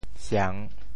siang3.mp3